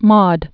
(môd)